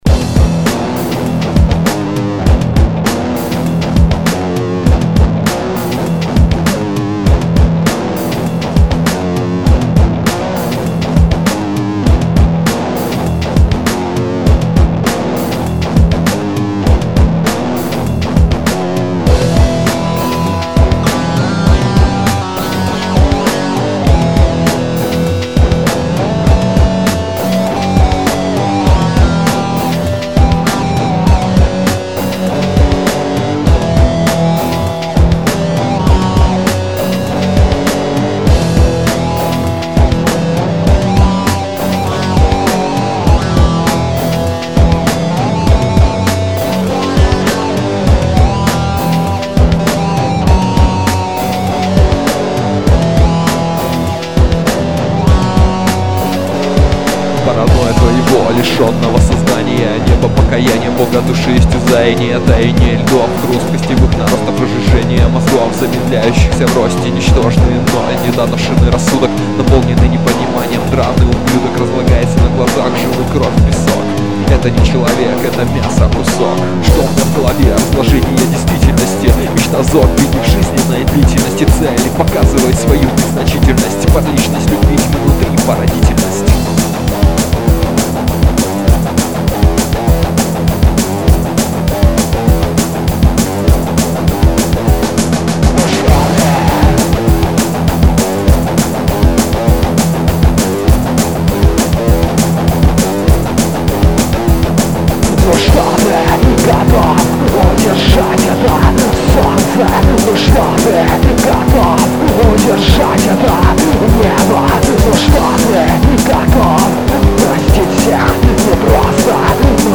Èíäàñòðèàë, Àëüòåðíàòèâà, Ýëåêòðîíèêà, Trash, Hardcore.